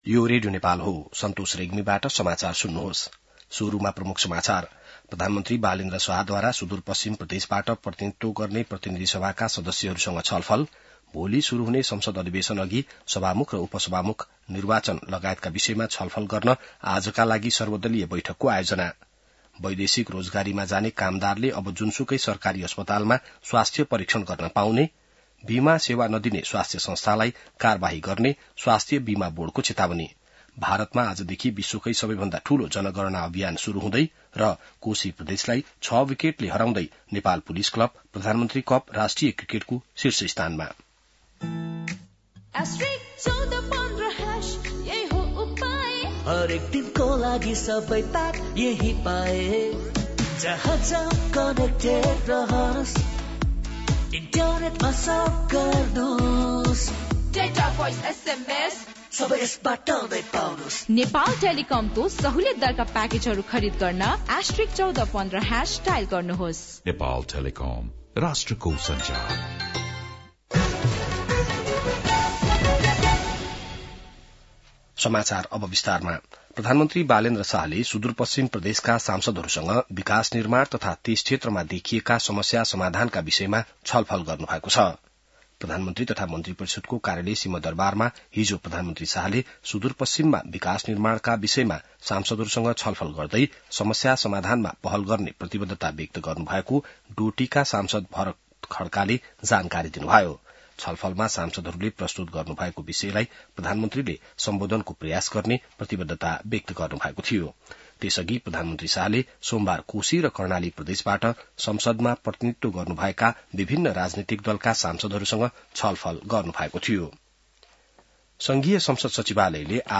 बिहान ७ बजेको नेपाली समाचार : १८ चैत , २०८२